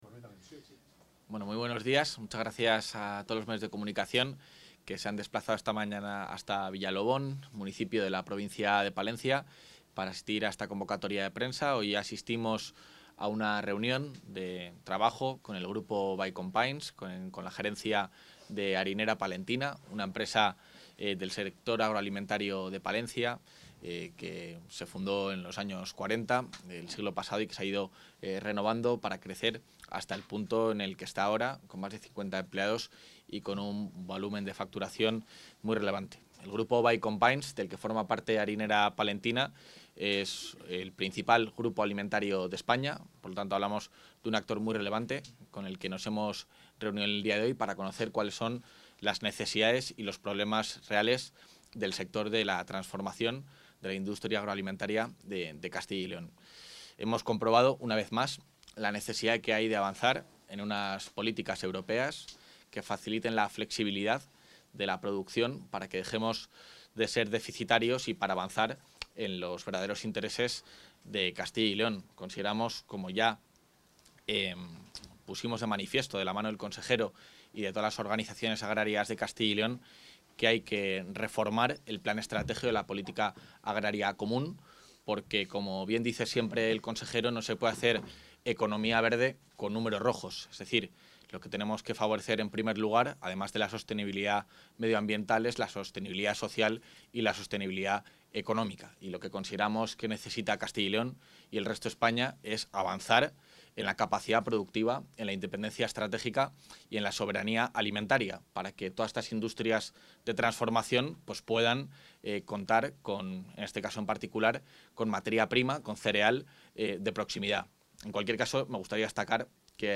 Intervención del vicepresidente.
Juan García-Gallardo urge la reforma de la PAC para reducir la dependencia exterior durante una visita a la harinera La Palentina